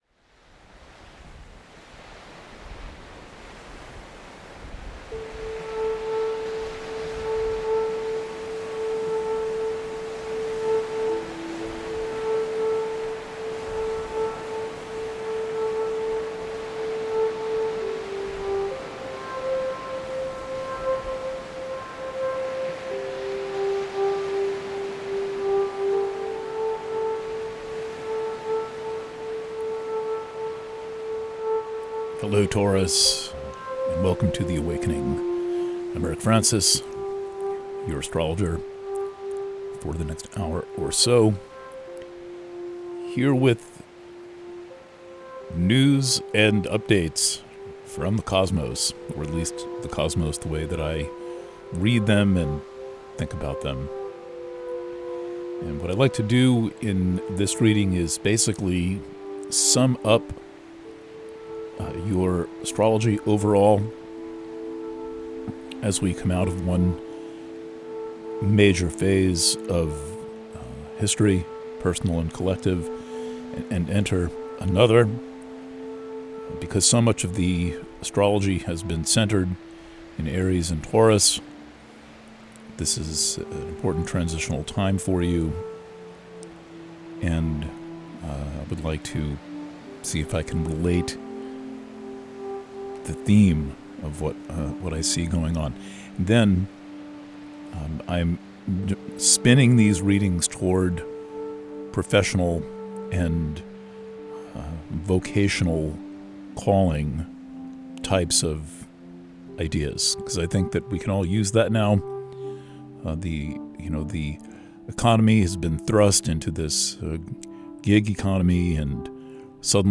Preview – The Awakening for Taurus Purchasing options for The Awakening Preview – Written reading Preview – The Awakening for Taurus – PDF Preview – Audio reading Alternate Player (Audio Only) Views: 88